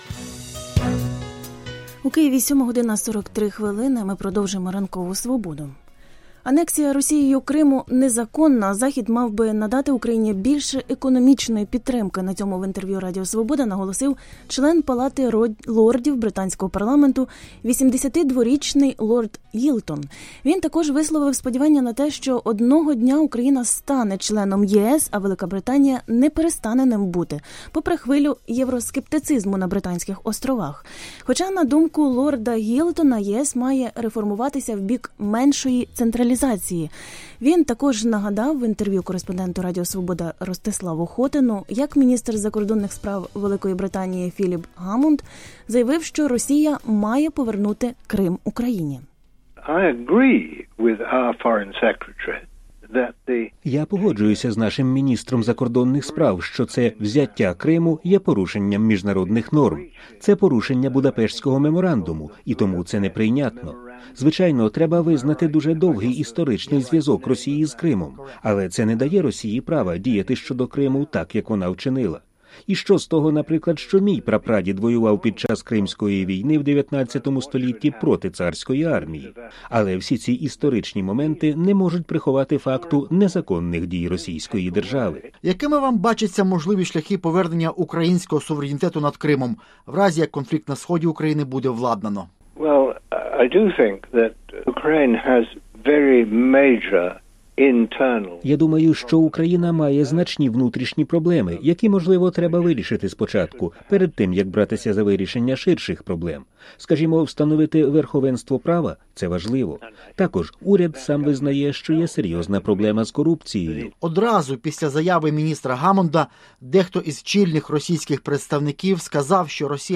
Анексія Росією Криму незаконна, а Захід мав би надавати Україні більше економічної підтримки. На цьому в інтервʼю Радіо Свобода наголосив член Палати лордів британського парламенту, 82-річний лорд Гілтон.